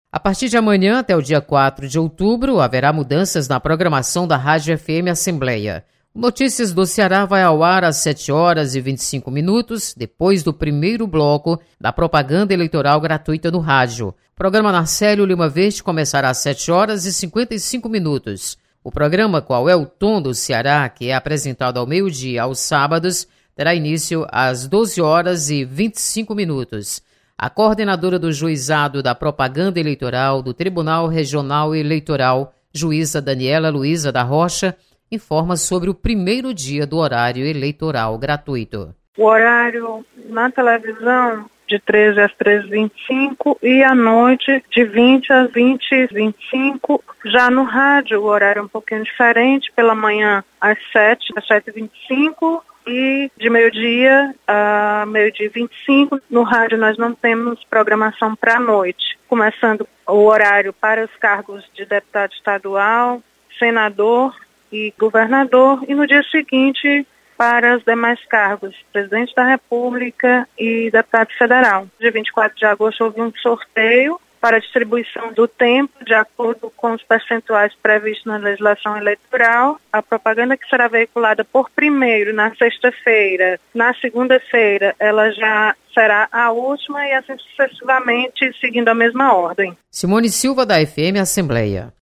Você está aqui: Início Comunicação Rádio FM Assembleia Notícias Eleições 2018